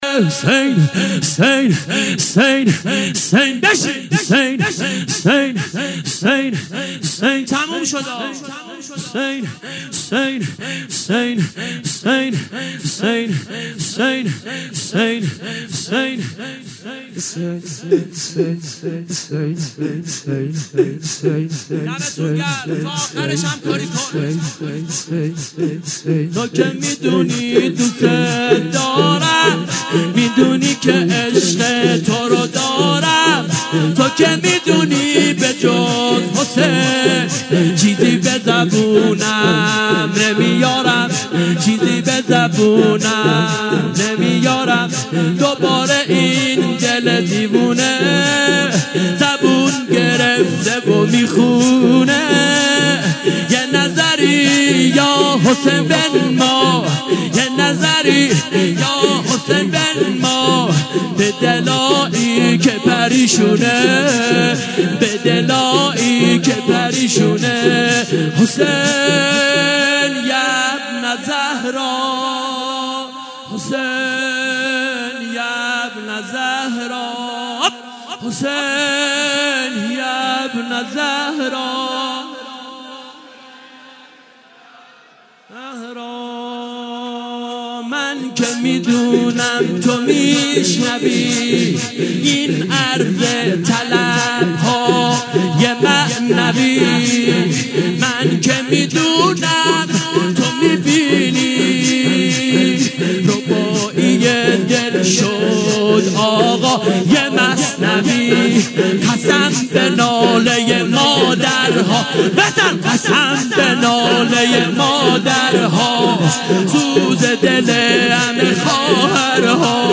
محرم الحرام- شب تاسوعا